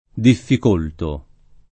diffik1lto] — antiq. difficultare: difficulto [diffik2lto] — rare, oltre che antiq., le forme accentate su -cult-; quasi solo teoriche le forme accentate su -colt-